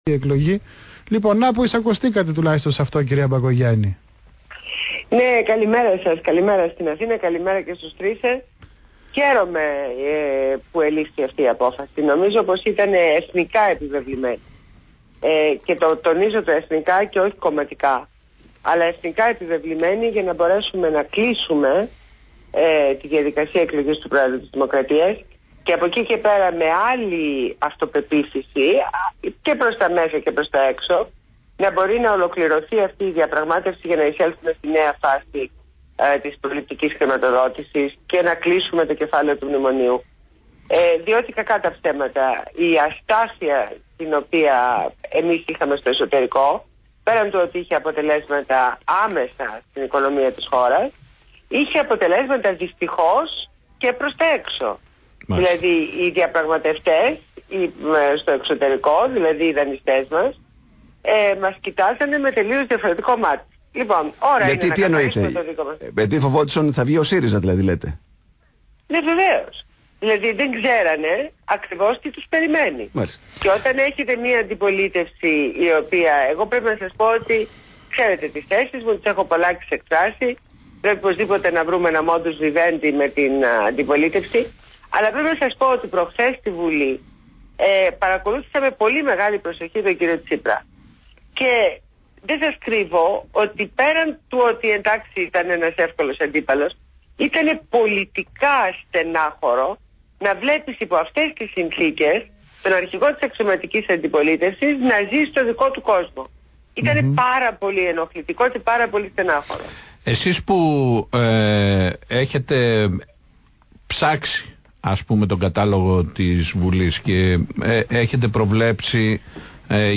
Συνέντευξη στο ραδιόφωνο ΒΗΜΑfm